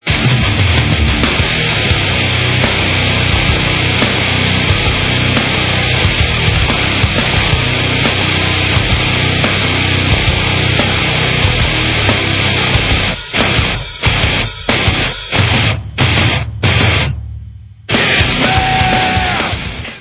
Rock - Speed/Thrash/Death Metal